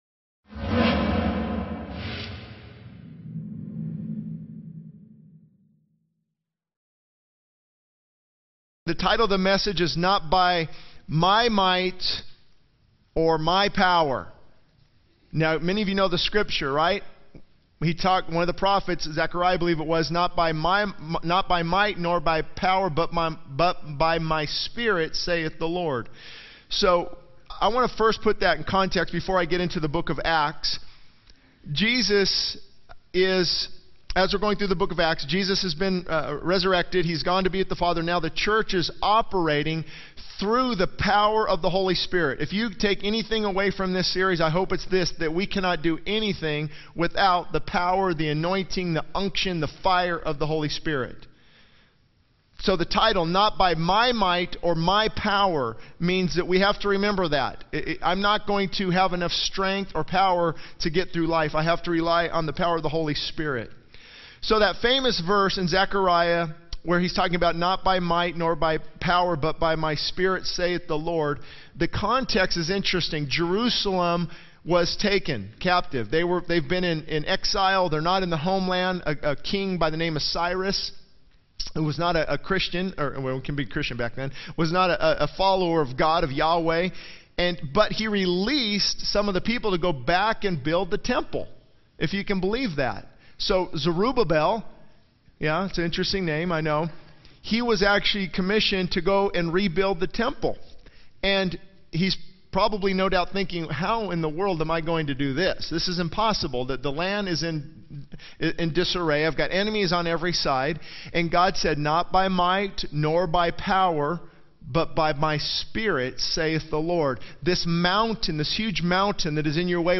He concludes by reminding the congregation that it is not by our might or power, but by God's Spirit that we can make a difference in the world.